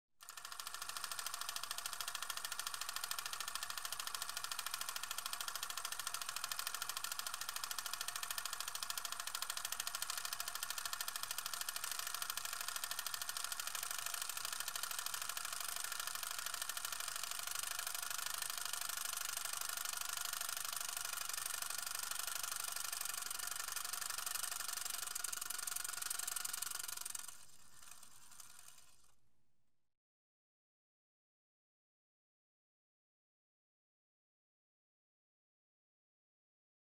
جلوه های صوتی
برچسب: دانلود آهنگ های افکت صوتی اشیاء